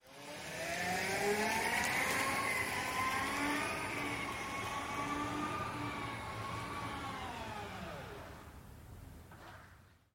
Шум электросамоката по асфальту